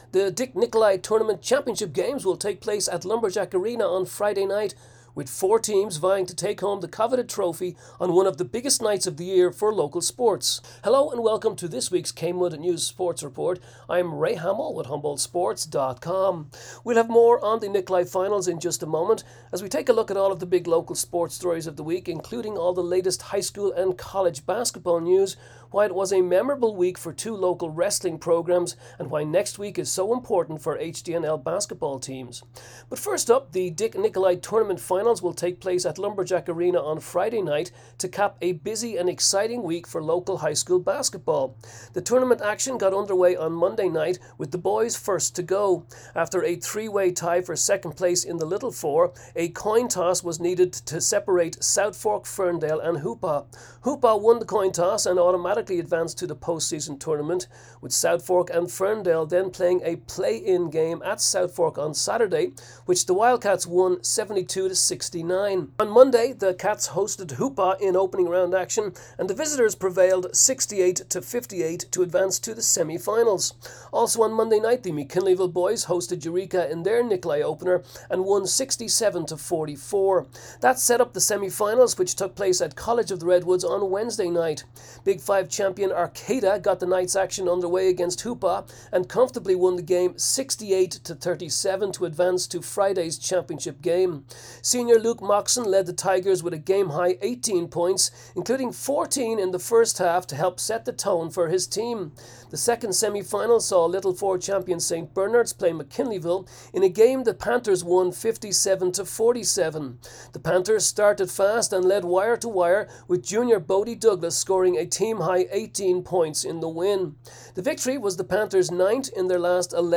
Feb 14 KMUD News Sports Report